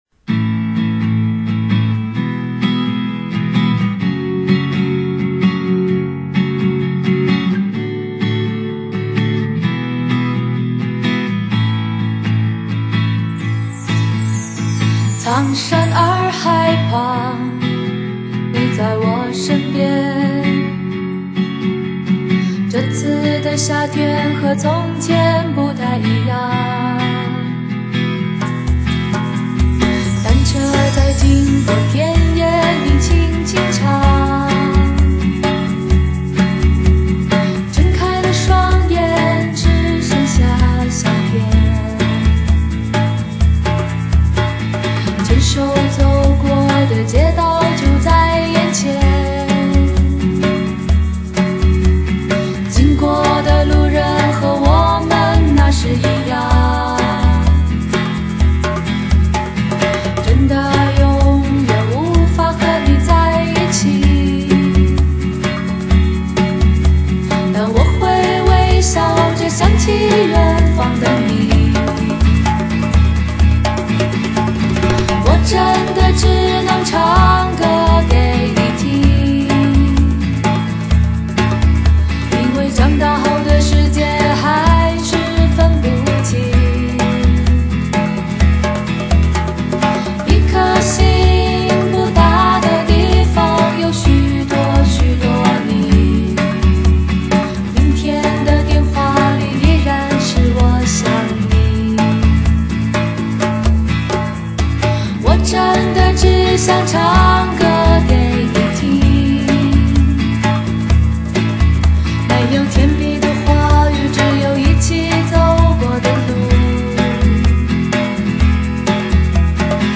淡定的午後
乾淨溫柔